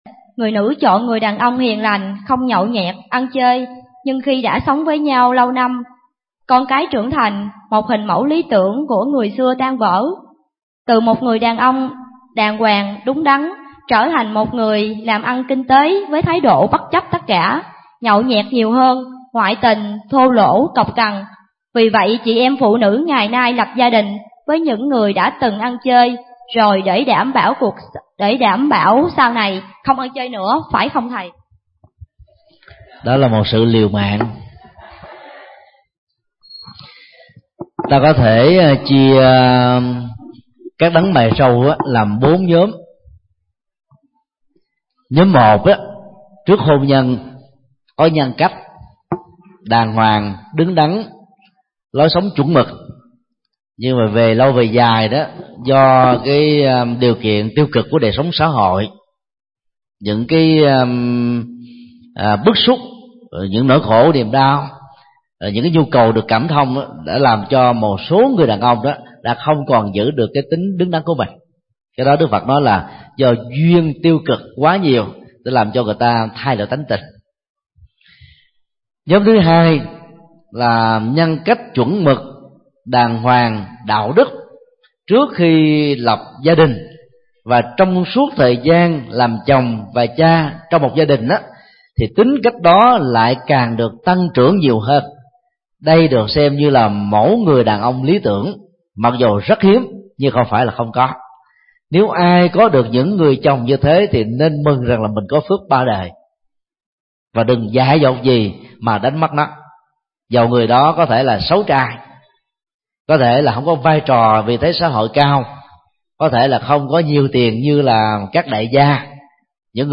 Vấn đáp: Các nhóm tâm lý của đấng mày râu